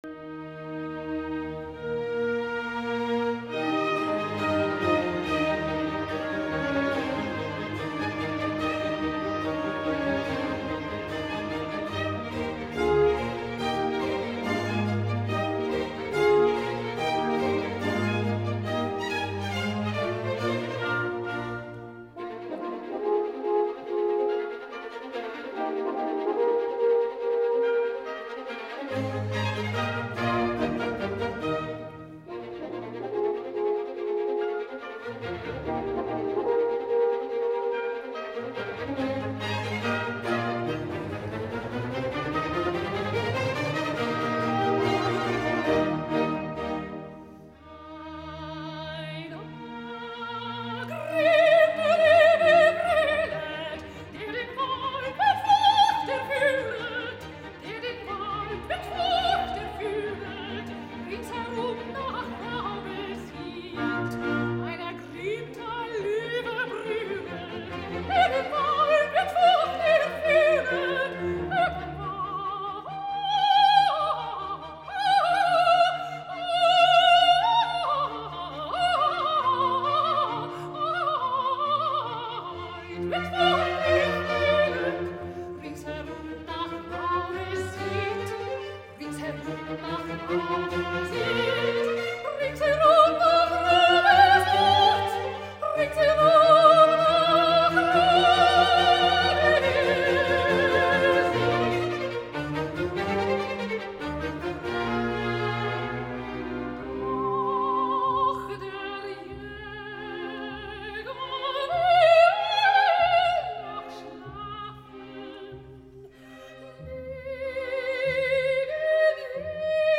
In der Schuldigkeit trat sie als „Göttliche Barmherzigkeit“ auf, u.a. mit der Bravour-Arie „Ein ergrimmter Löwe brüllet, der den Wald mit Forcht erfüllet, rings herum nach Raube sieht…“